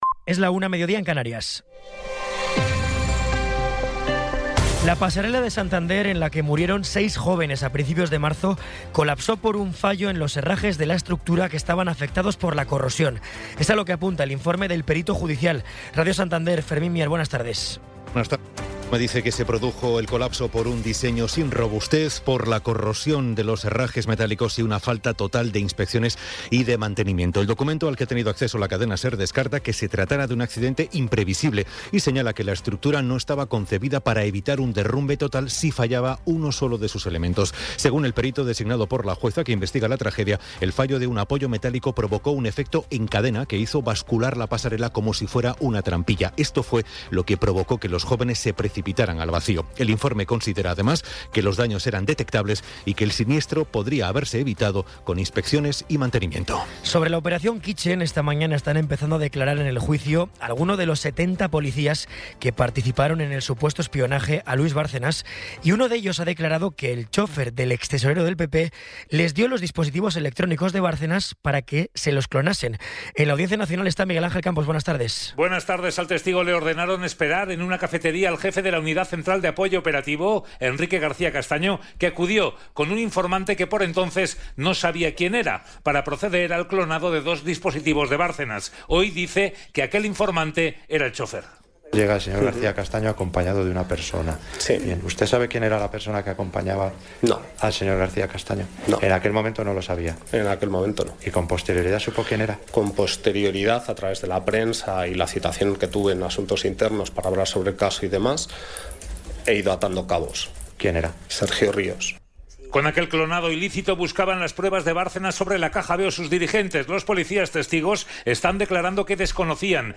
Resumen informativo con las noticias más destacadas del 16 de abril de 2026 a la una de la tarde.